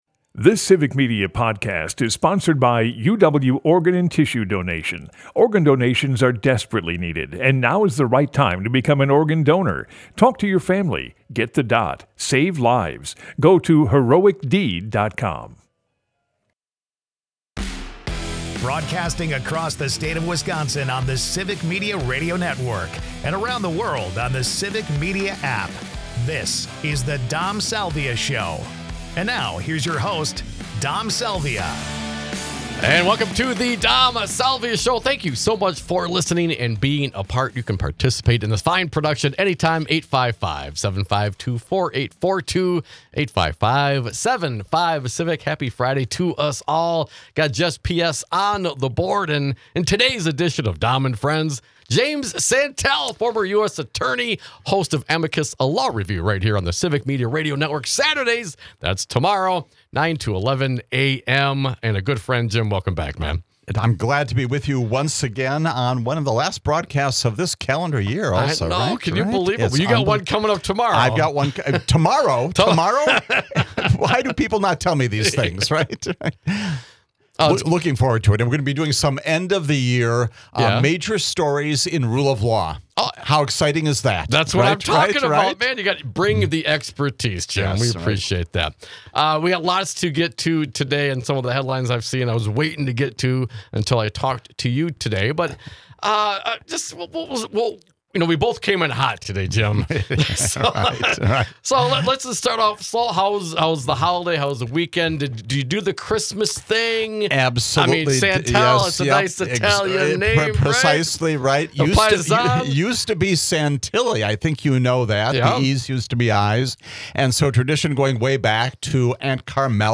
Jim Santelle cohosts to cover legal matters. Could the Constitution's 3rd section of the 14th Amendment prevent Trump from retaking office?